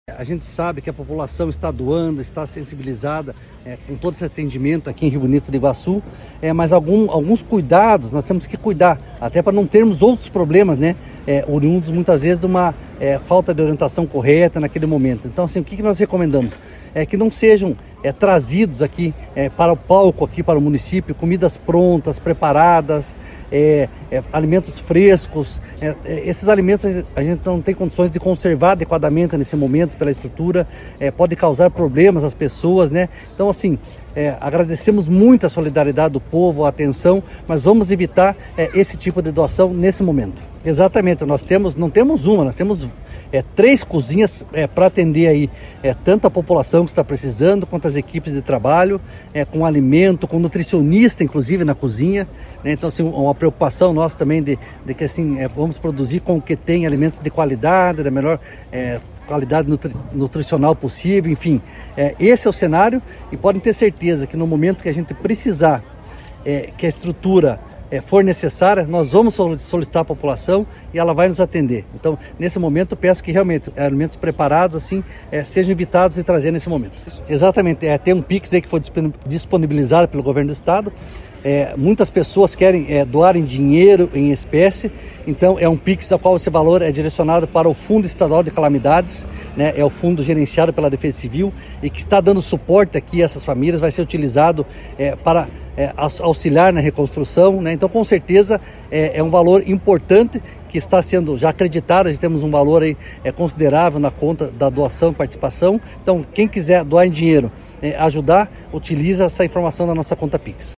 Sonora do coordenador estadual da Defesa Civil, Coronel Fernando Schunig, sobre as orientações para doações a Rio Bonito do Iguaçu